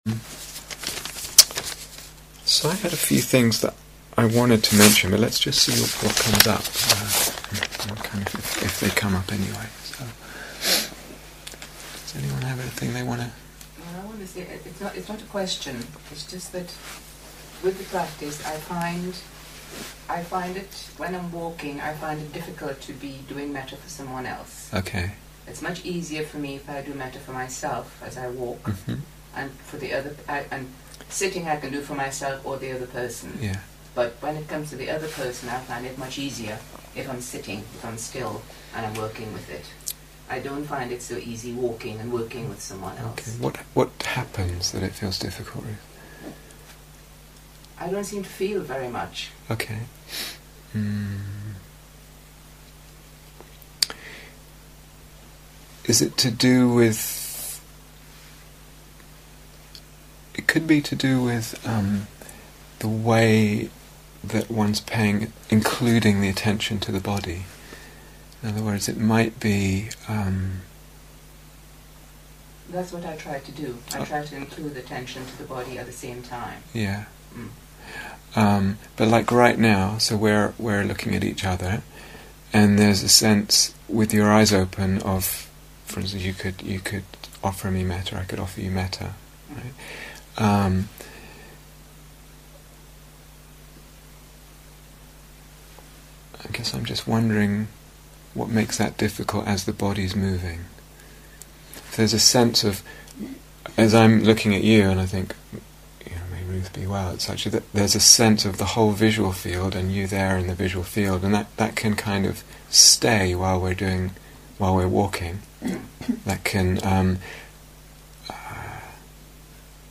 Refining the Mettā Practice (Question and Answer Session 1)